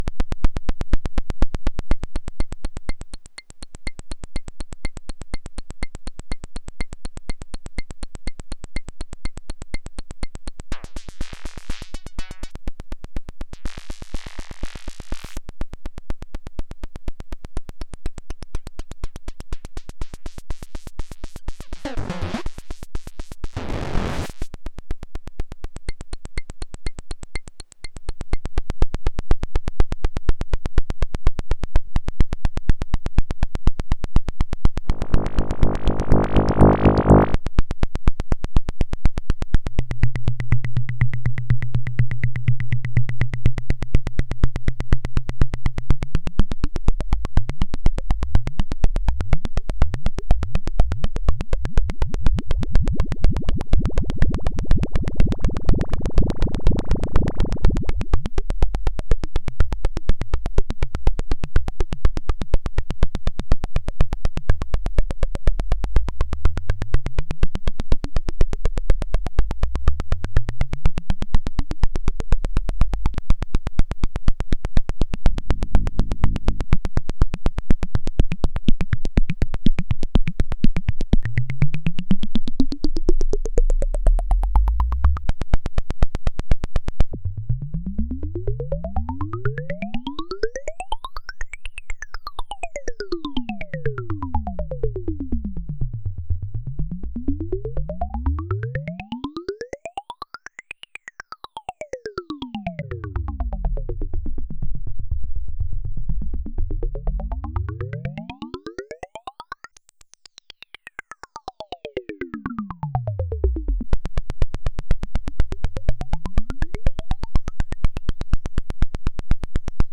Ну как, нормально булькает?